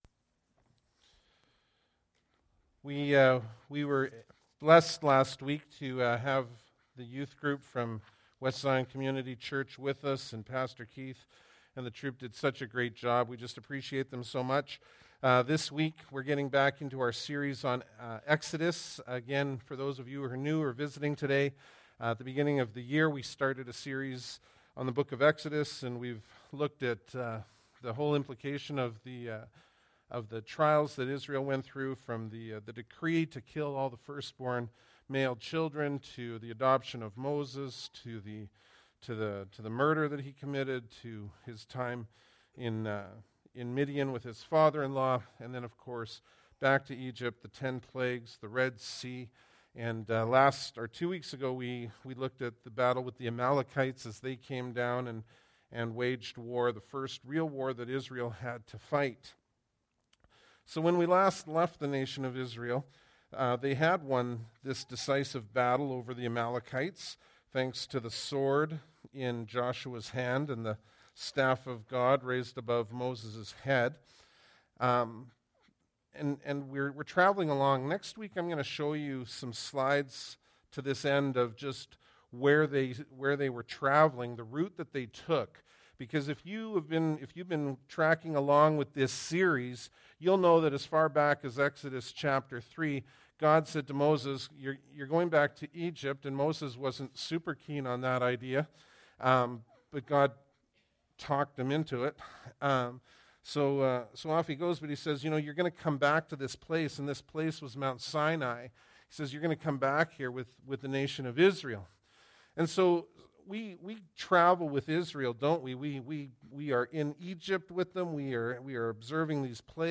Bible Text: Exodus 18 | Preacher: